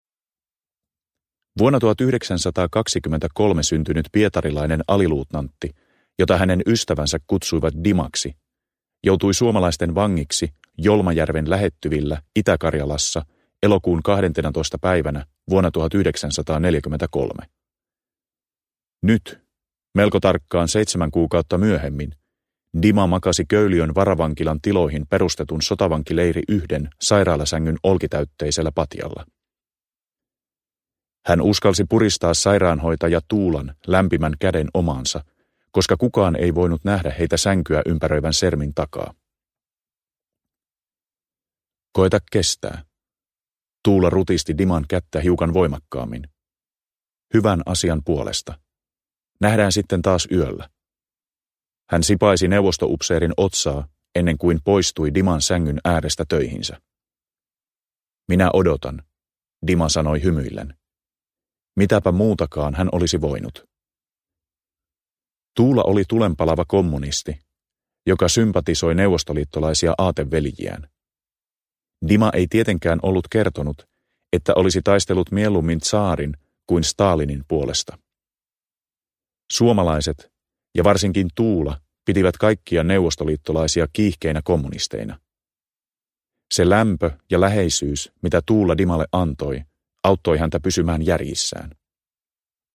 Venäläinen vieras – Ljudbok – Laddas ner
Uppläsare: Jukka Peltola